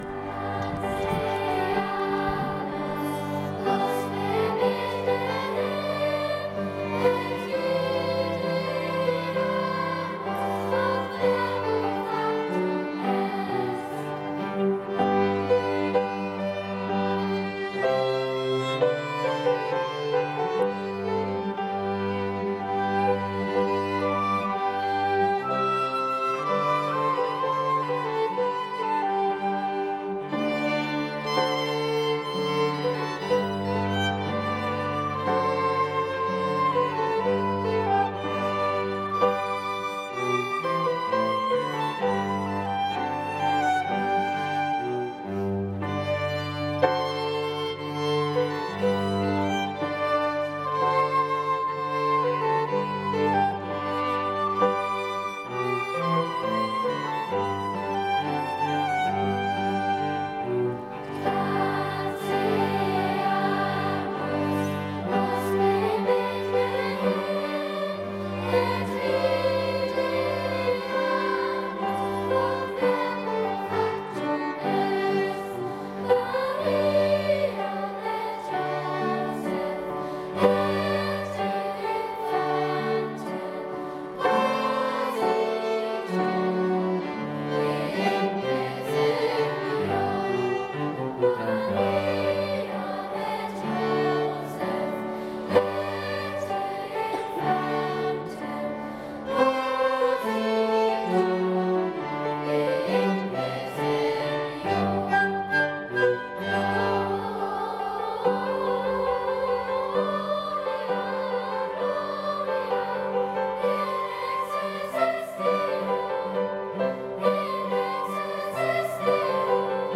Mit wunderbaren Chorklängen, sanften Gitarren- und Bandsounds, atmosphärischer orchestraler Sinfonik und Orgelmusik von der Empore stimmten uns die großen musikalischen Ensembles am 11. Dezember in St. Gabriel auf Weihnachten 2024 ein.
"Transeamus usque Bethlehem" von Arthur Schnabel, Unterstufenchor "Cantate Omnes" Jgst. 5-7